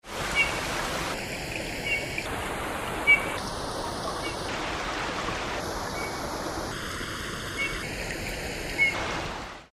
Снегирь обыкновенный
Позывка. Кузнецкий Алатау. 23.04.2009